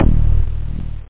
303-hardfloorbass.mp3